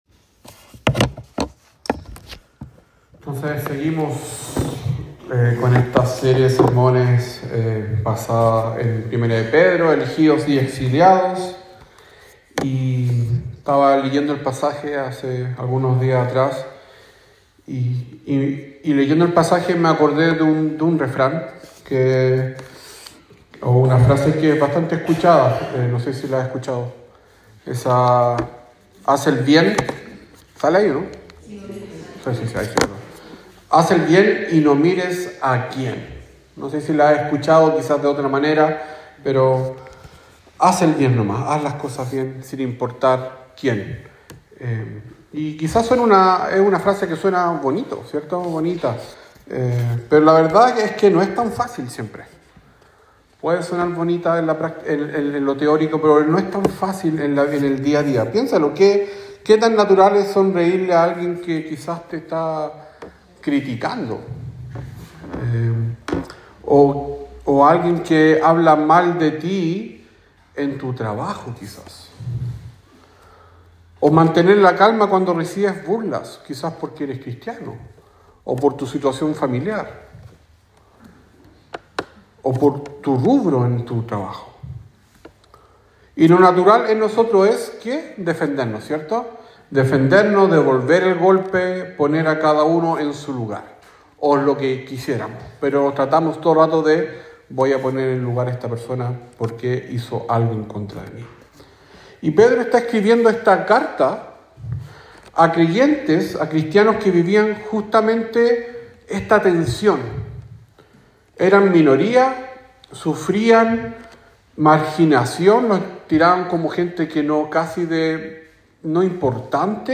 Sermón sobre 1 Pedro 3 : 8 - 22